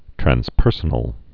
(trăns-pûrsə-nəl, trănz-)